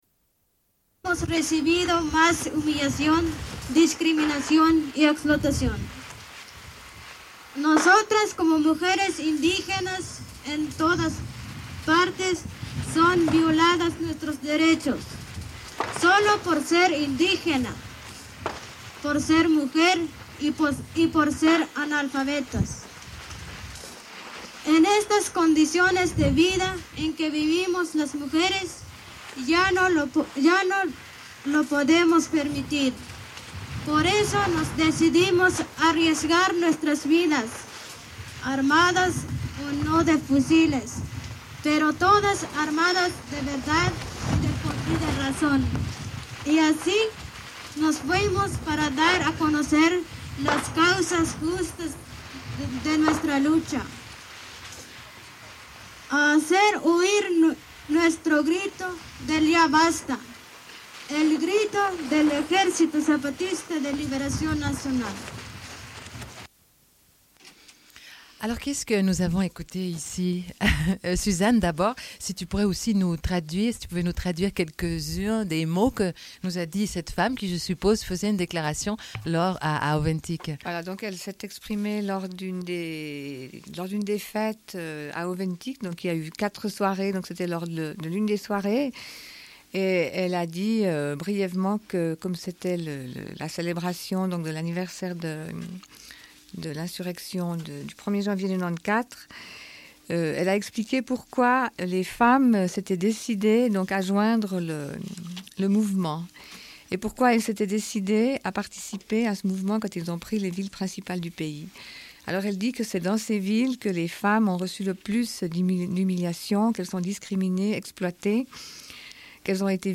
Diffusion d'enregistrements pris sur place par les deux femmes qui ont rencontré d'autres femmes sur place.
Radio